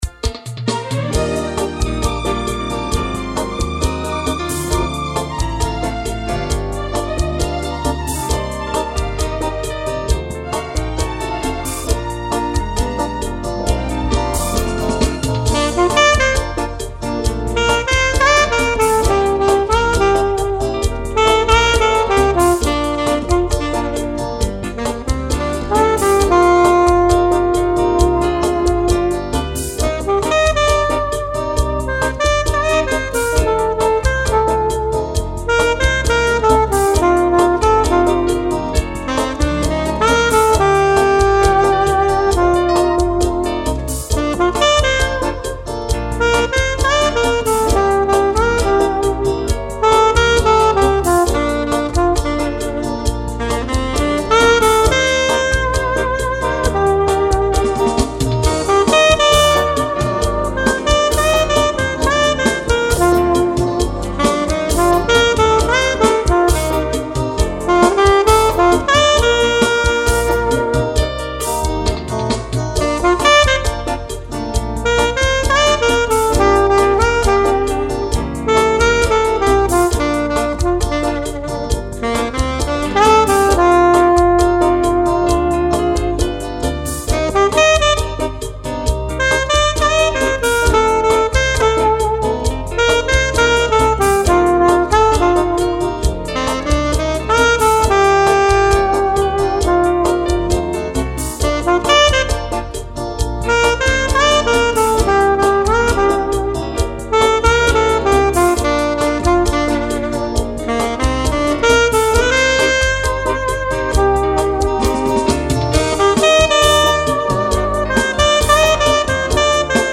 846   04:59:00   Faixa:     Bolero